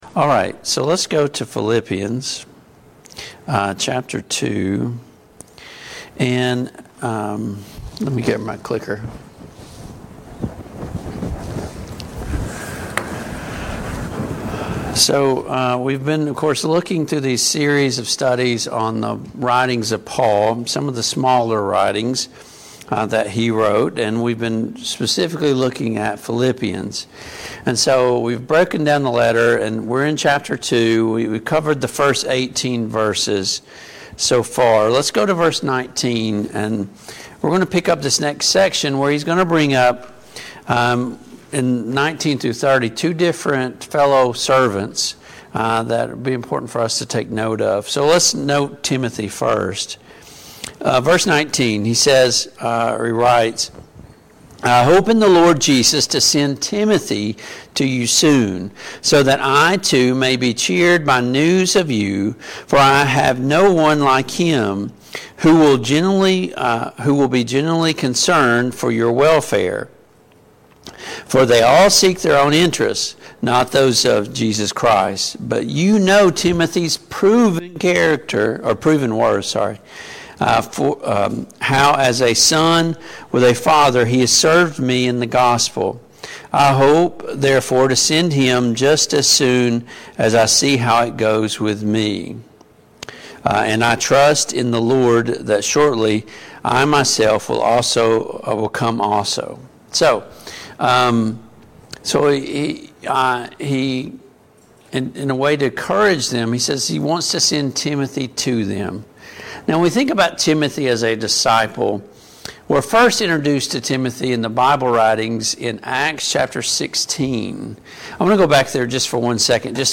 Passage: Philippians 2:19-30, Philippians 3:1-11 Service Type: Mid-Week Bible Study